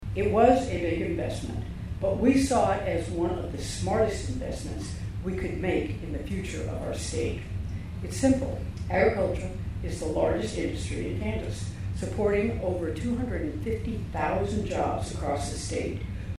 A groundbreaking ceremony for a new Agronomy Research and Innovation Center at Kansas State University had to be moved indoors Monday, due to rain, leading to a standing-room only crowd of guests inside the university’s Agronomy Education Center.
Gov. Laura Kelly addresses the gathering inside the Agronomy Education Center Monday.